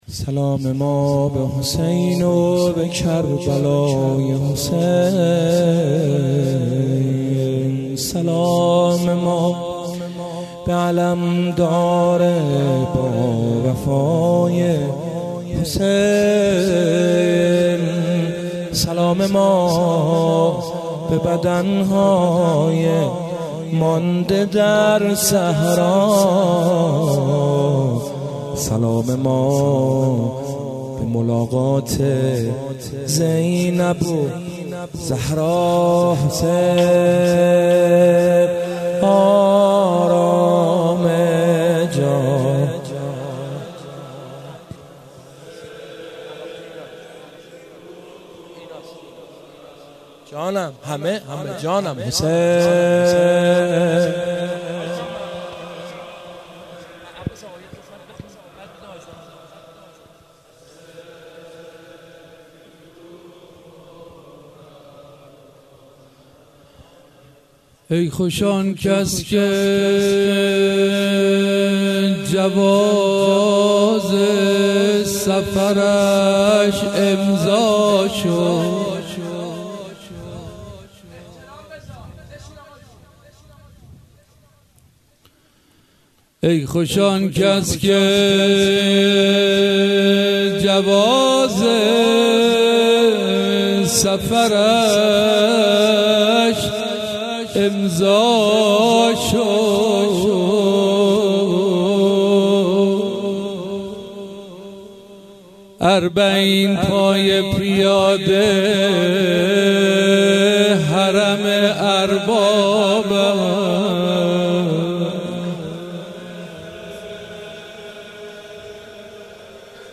شعر خوانی
روضه